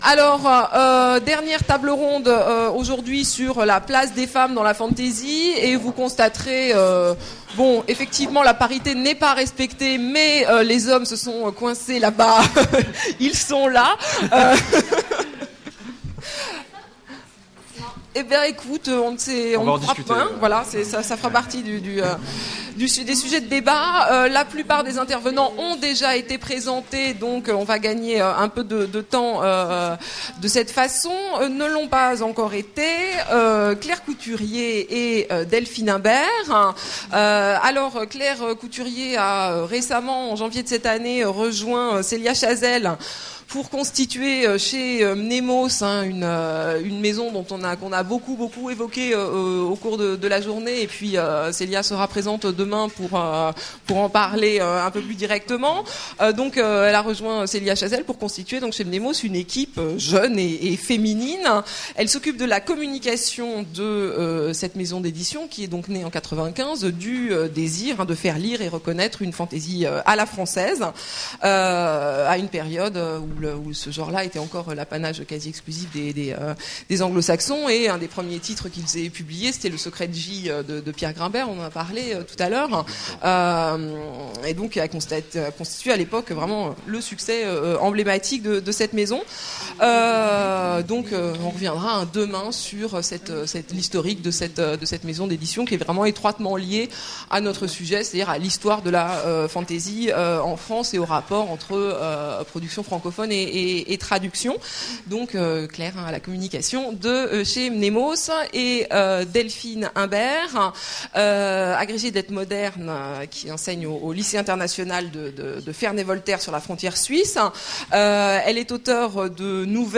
Journée de la fantasy - Conférence : La place des femmes dans la fantasy en France
table ronde